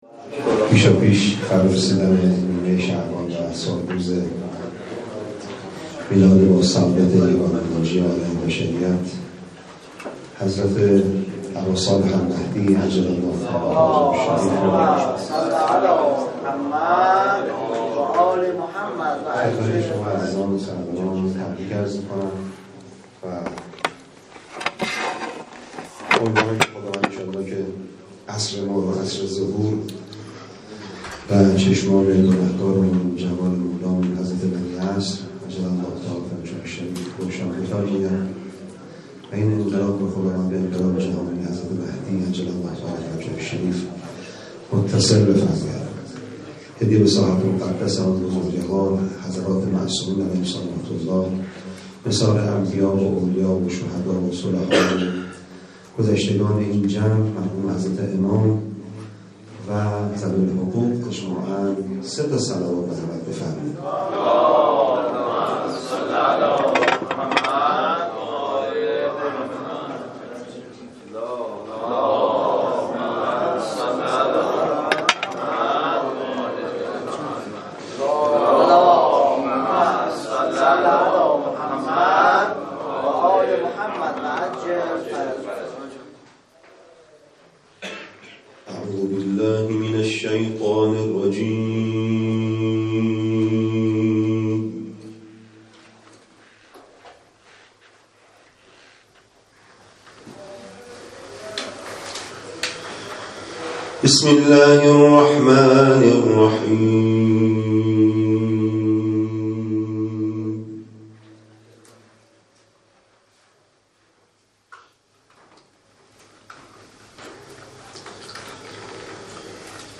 نخستین «کرسی تلاوت تسنیم» پیش از ظهر امروز، یکشنبه 17 اردیبهشت‌ماه در باشگاه خبرنگاران پویا برگزار شد